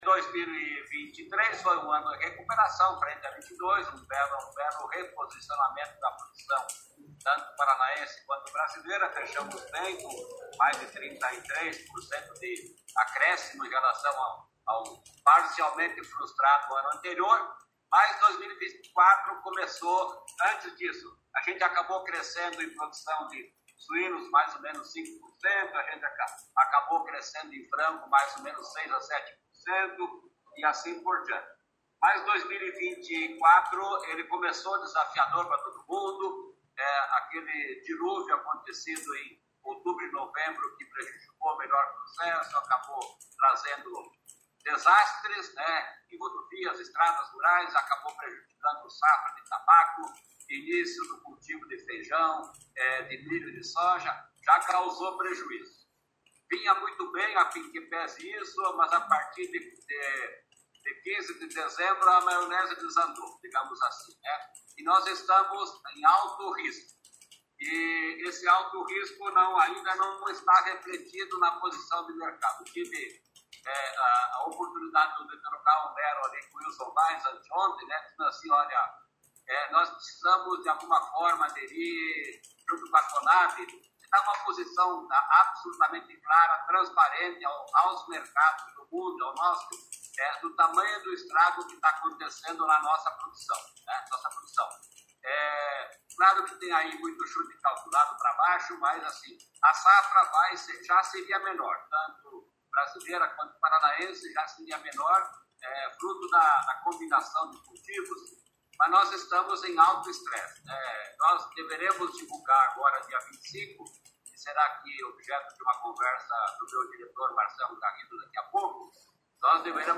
Sonora do secretário Estadual da Agricultura, Norberto Ortigara, sobre o impacto do clima na safra de soja 2023-2024